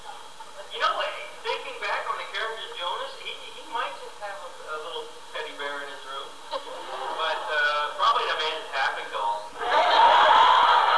Gatecon 2003